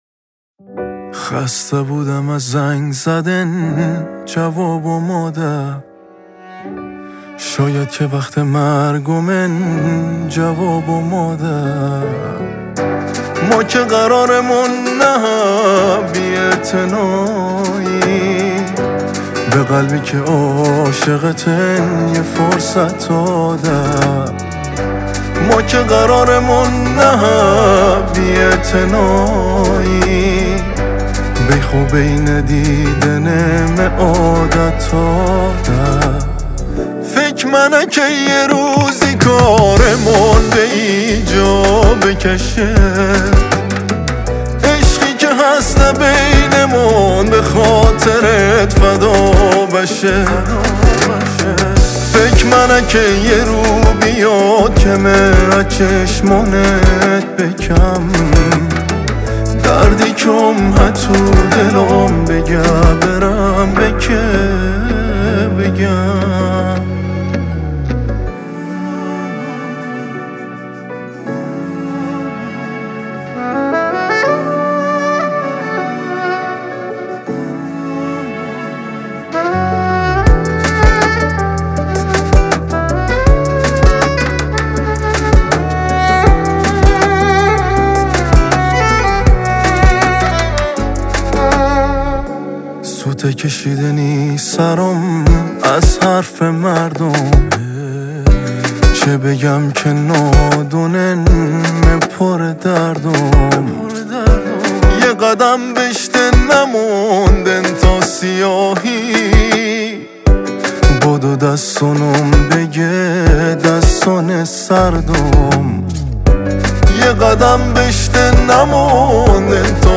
آهنگ ایرانی